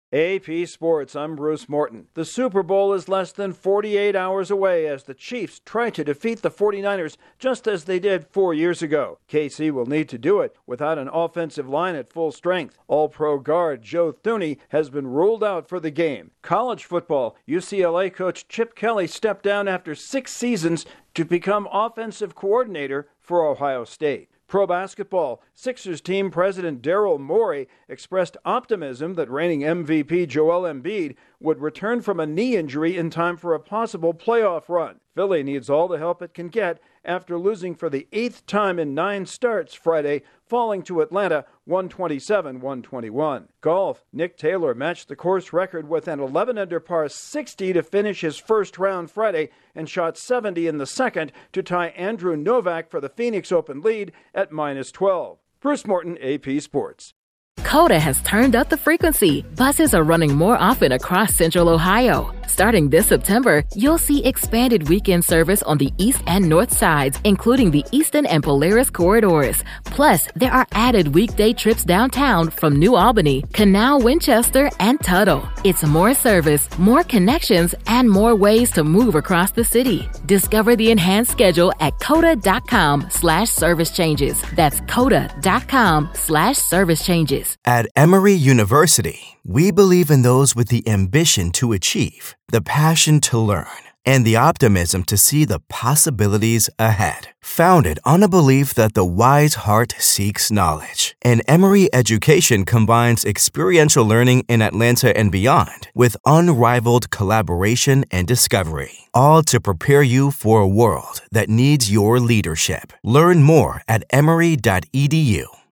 The Chiefs and 49ers continue with late prep work for Super Bowl 58, Chip Kelly has a new job, the 76ers fall again and Nick Taylor torches the course at the Phoenix Open. Correspondent